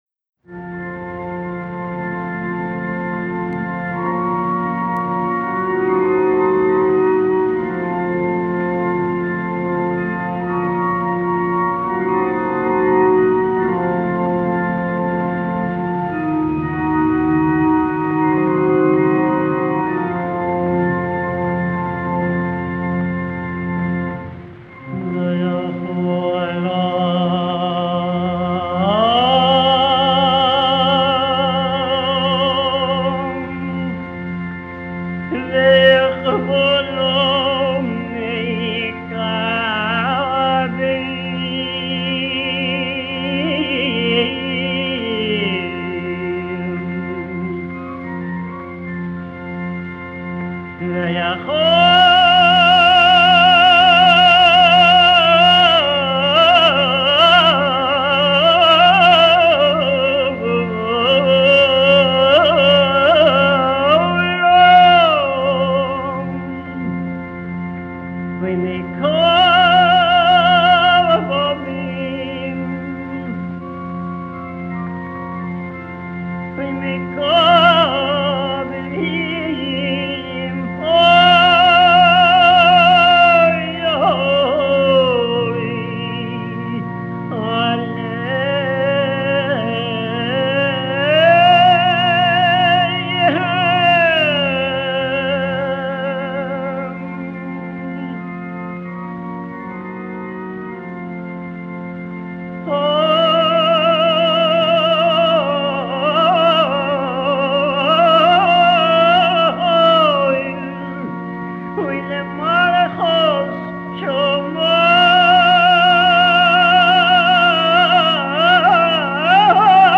Cantor and composer.